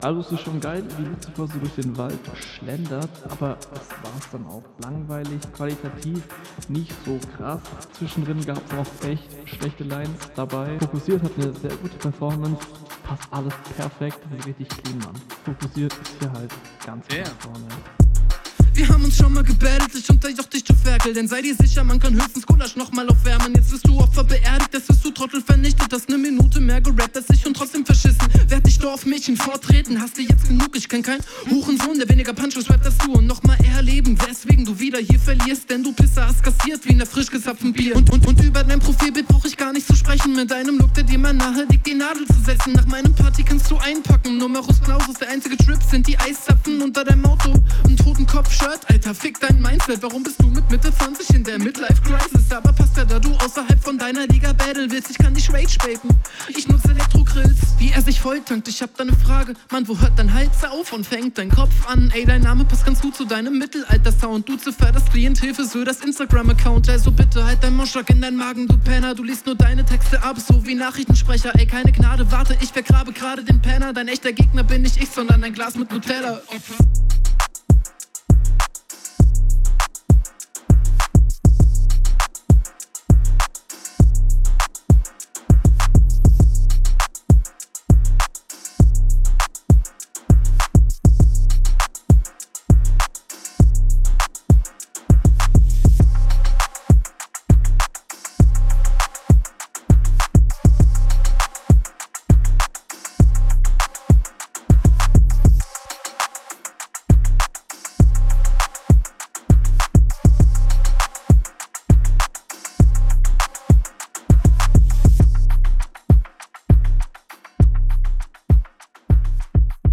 deuuutlich besser als deine rr. mix/master funktioniert hier deutlich besser, ist aber auch wieder kein …
Der Flow ist hier definitiv besser als auf dem anderen Beat, das geht gut nach …